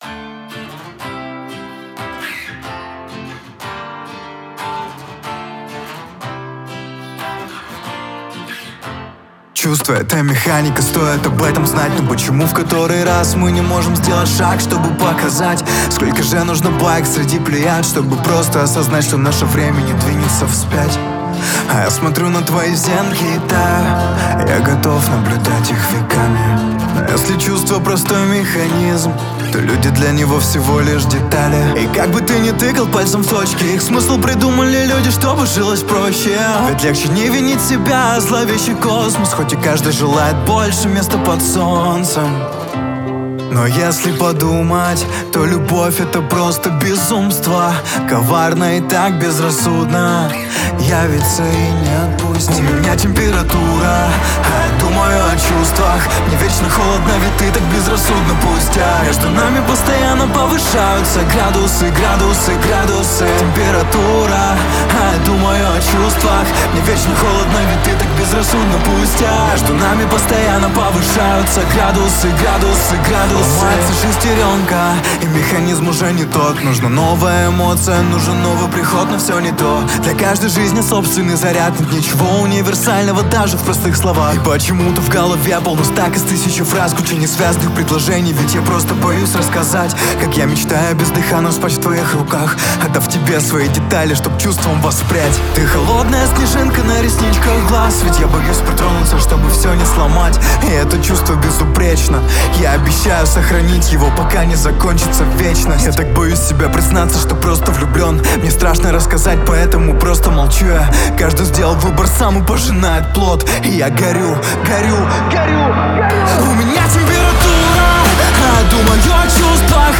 Композиция звучит чисто и подходит для любого настроения.